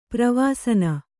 ♪ pravāsana